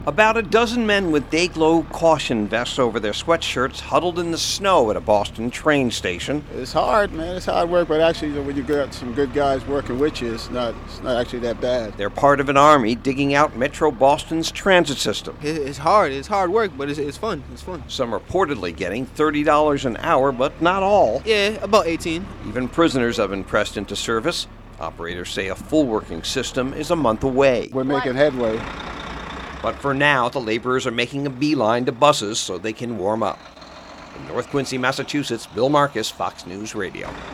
(NORTH QUINCY, MASSACHUSETTS) FEB 17 – UNDERGROUND LINES IN THE METROPOLITAN BOSTON SUBWAY AND TROLLEY LINE SYSTEM ARE WORKING TODAY BUT NOT THE ONES OUTSIDE UNDER SEVEN FEET OF SNOW.